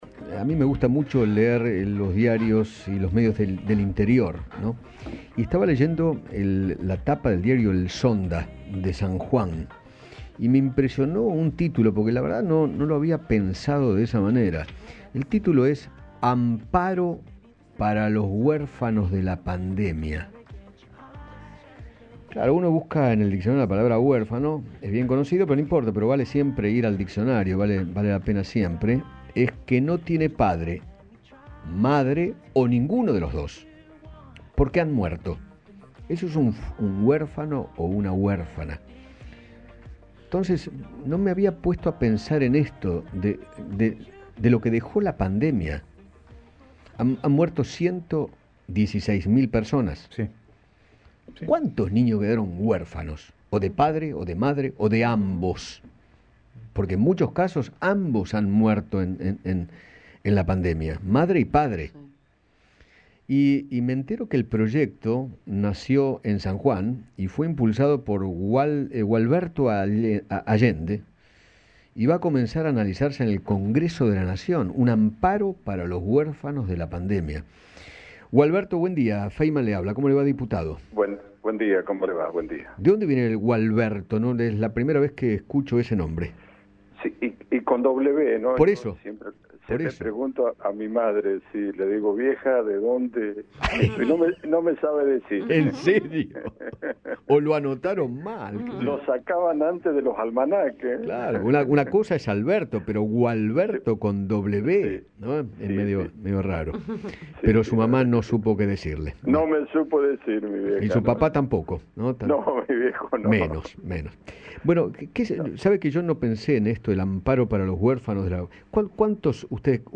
Walberto Allende, diputado nacional, conversó con Eduardo Feinmann sobre el proyecto de Ley que presentó para que aquellos niños que perdieron a sus padres por la pandemia reciban un resarcimiento económico hasta que sean mayores de edad.